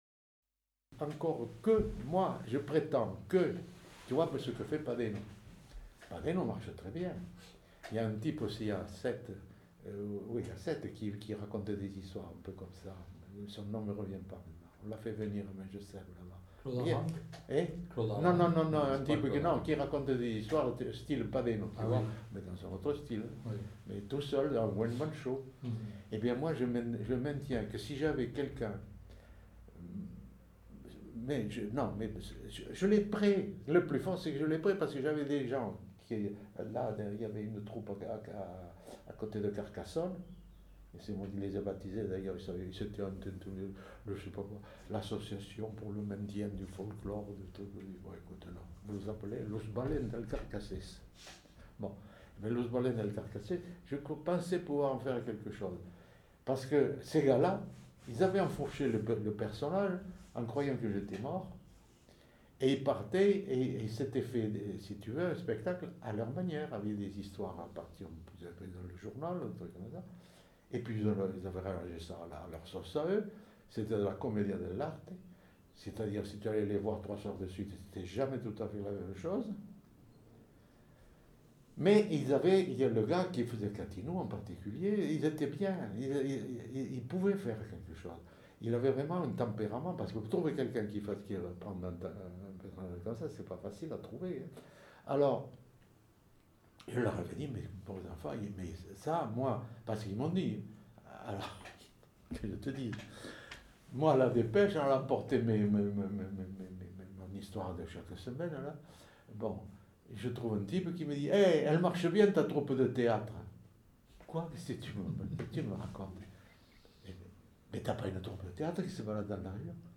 Genre : récit de vie